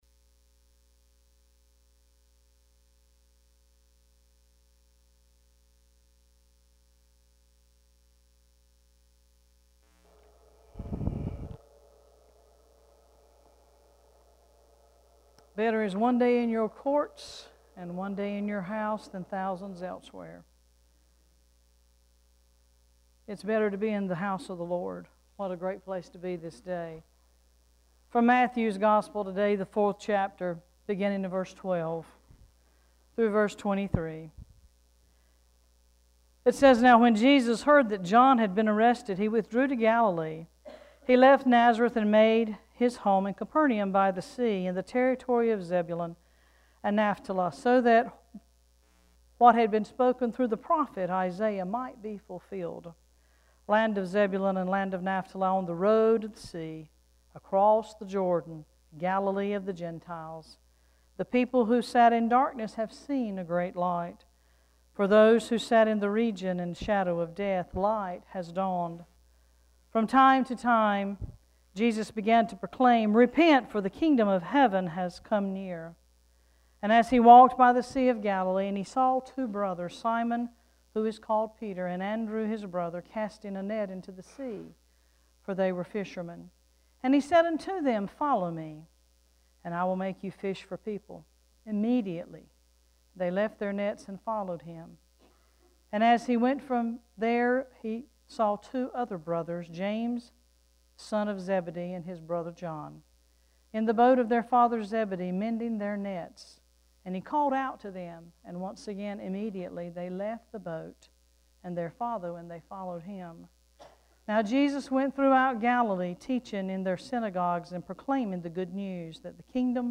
Worship Service 1-22-17: “Going Fishing With Jesus”